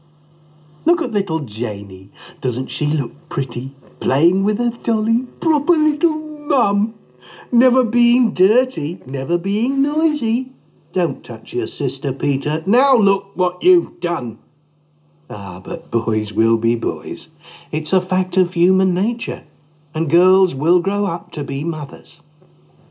Boyswillbeboys_stanza2.mp3